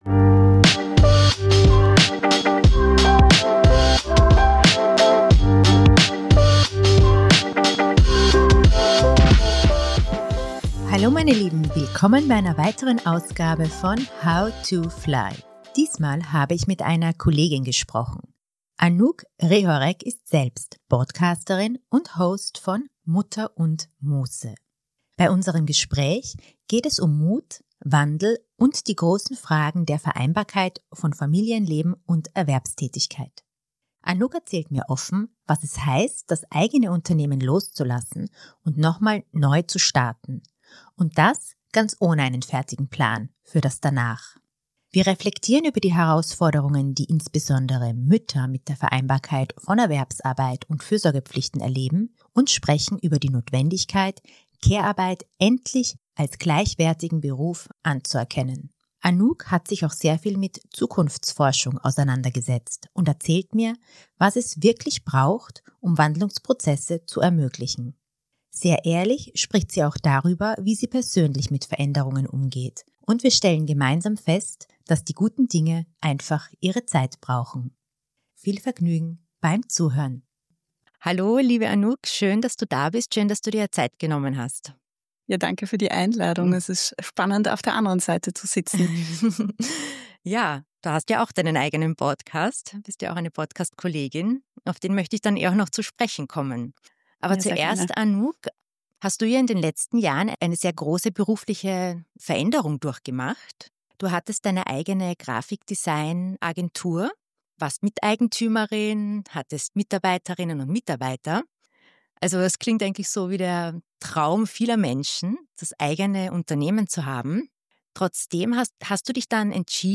Diesmal habe ich mit einer Kollegin gesprochen: